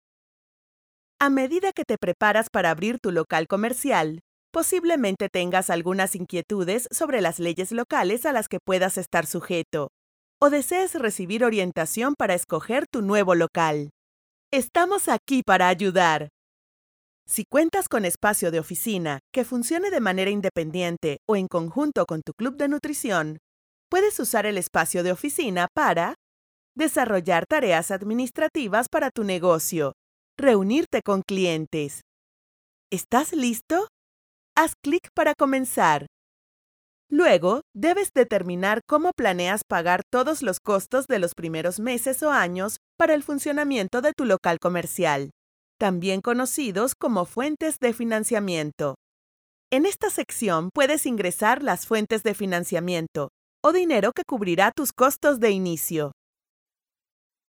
Female
Character, Confident, Conversational, Corporate, Friendly, Natural, Young
demo_characters_2024.mp3
Microphone: Audio-Technica AT4030a Cardioid Condenser Microphone